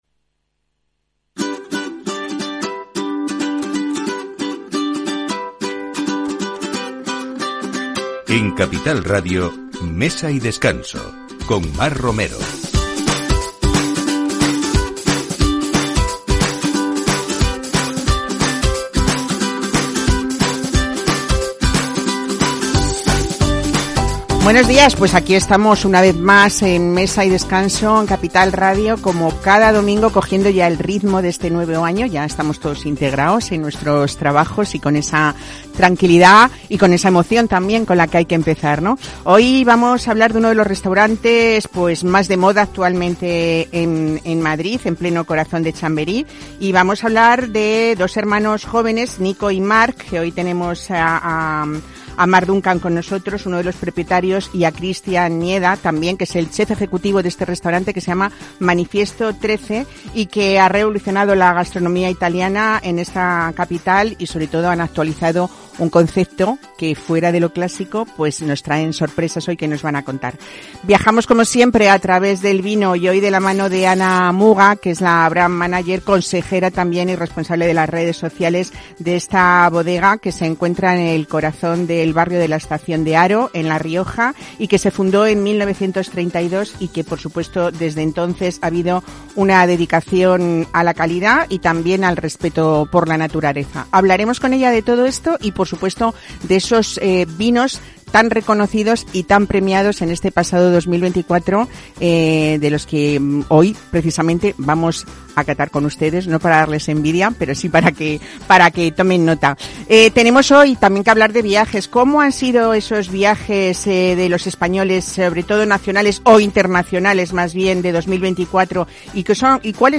Programa dedicado a la actualidad gastronómica y al mundo del vino, el enoturismo y lugares de interés para disfrutar. Expertos en enología y destacados sumilleres se alternan cada semana en un espacio para conocer los mejores vinos aconsejados por los mejores especialistas. El repaso a la actualidad gastronómica con profesionales de la hostelería, cocineros y restauradores.